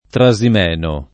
Trasimeno [ tra @ im $ no ]